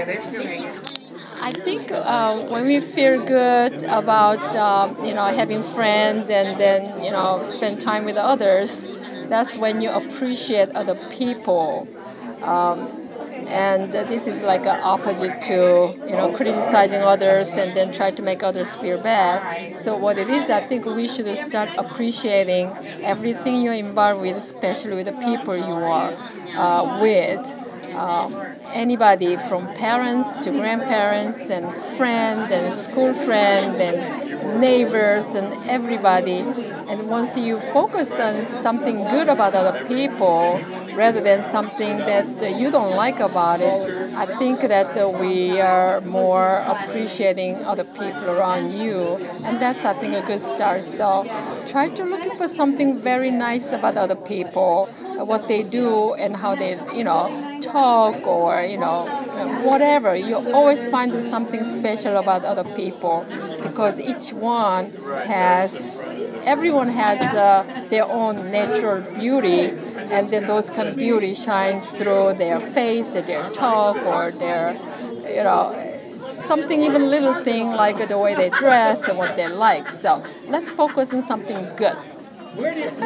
These stories were recorded at the opening of the Yellow Springs Arts Council’s show “Art for Social Change” in response to sharing our progress at Mills Lawn with Project Peace.